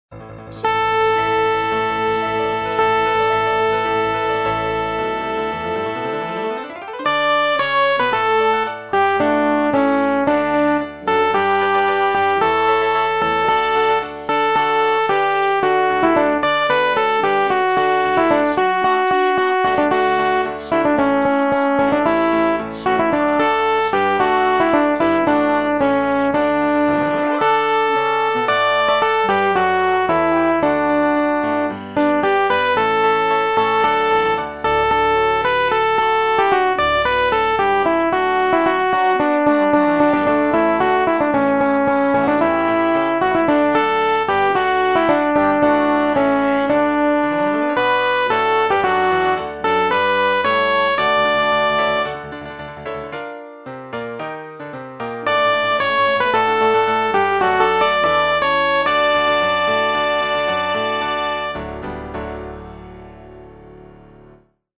浦和ｗ用　4パート編曲版　（音色的に、オリジナル8パートとそれほど変わらない）
アルト（歌詞付き）